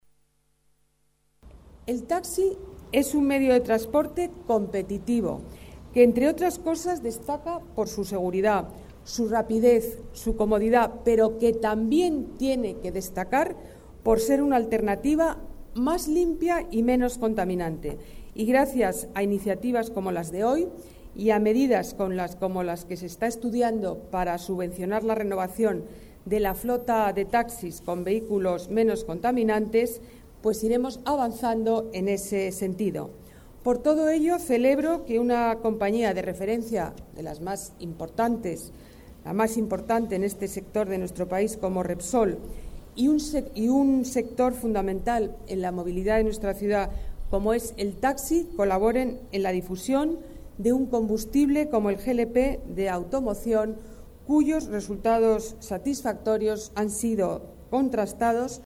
Nueva ventana:Declaraciones de la delegada de Medio Ambiente y Movilidad, Ana Botella